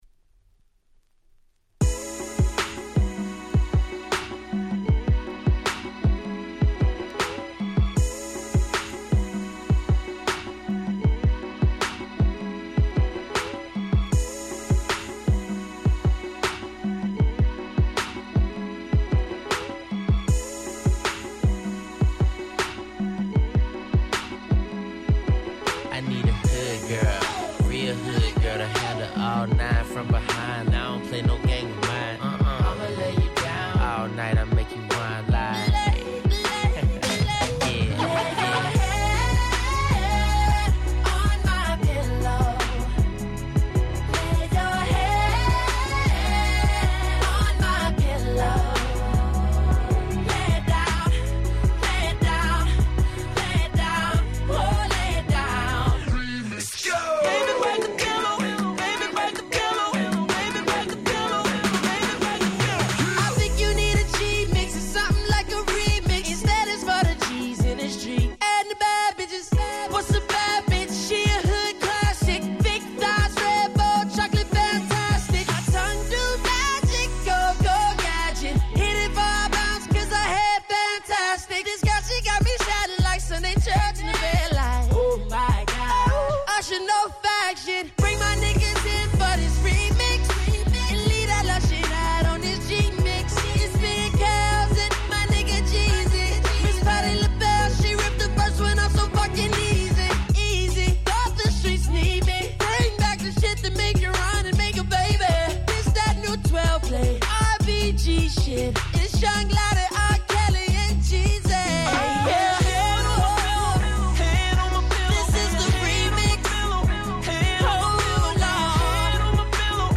10' Super Hit R&B !!